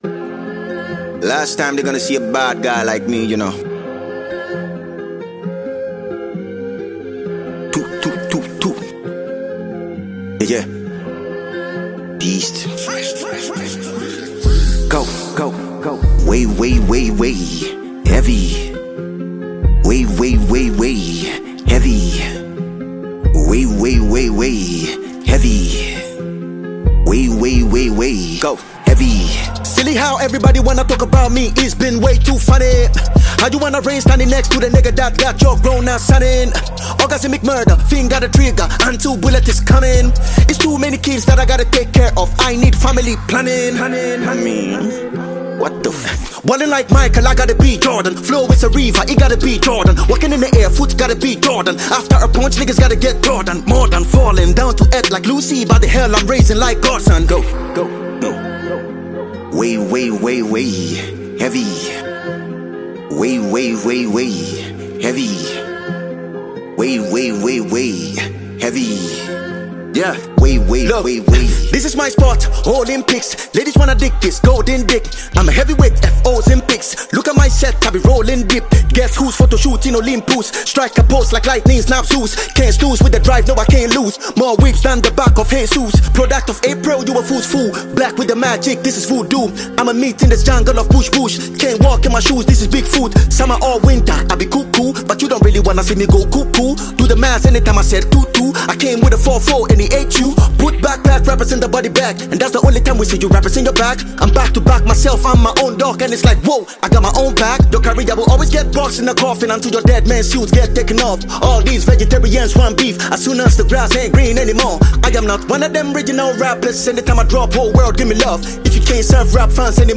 Lyrically Gifted Ghanaian rapper